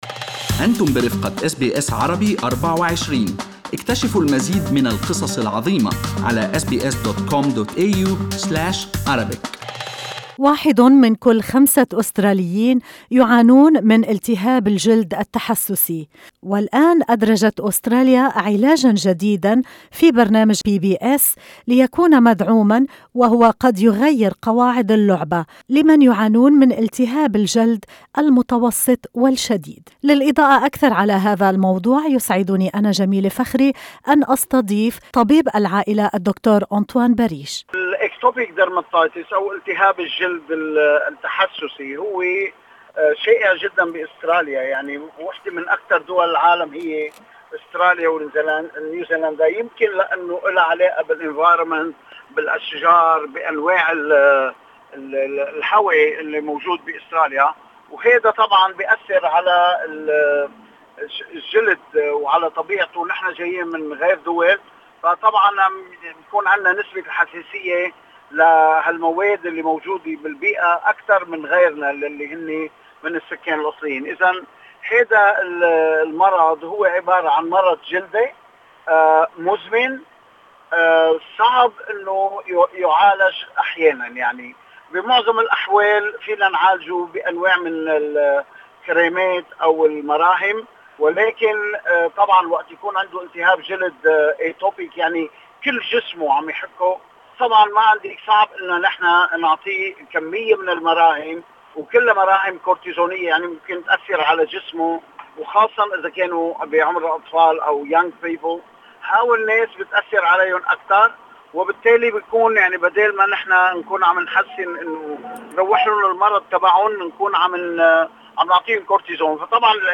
وفي لقاء له مع أس بي أس عربي24